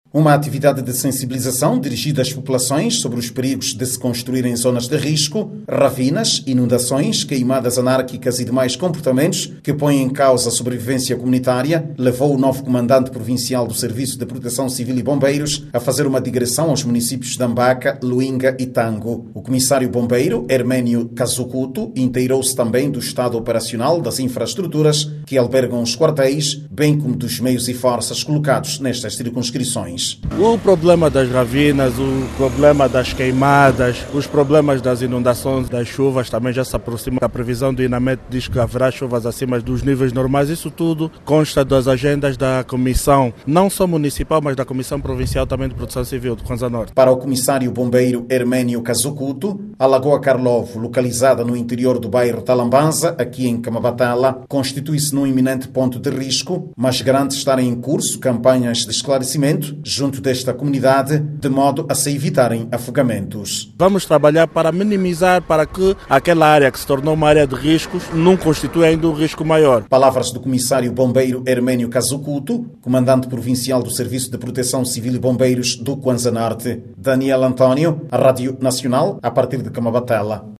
CUANZA-NORTE-BOMBRIROS-23HRS.mp3